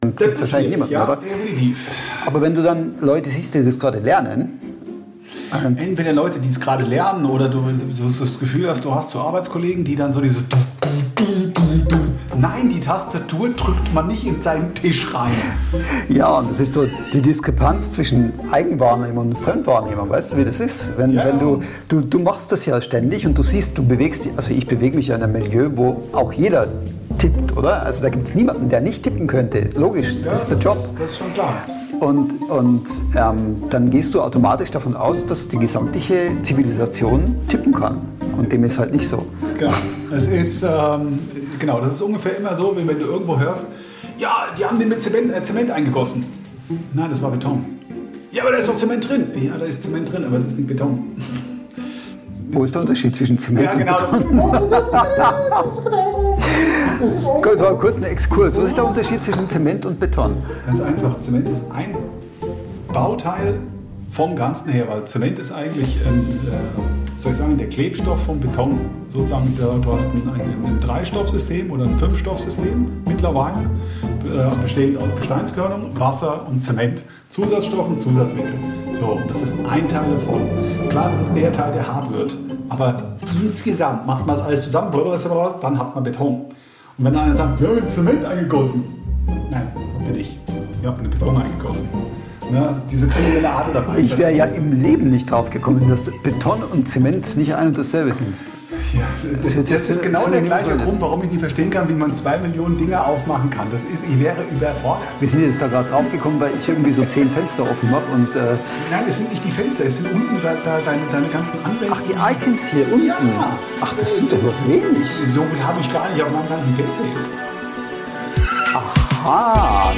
Natürlich darf ein Mikrofon-Check nicht fehlen — nach zehn Episoden zeigt sich: Blick aufs Mikro lohnt sich immer, weil man manchmal mehr Luft als Audio aufgenommen hat.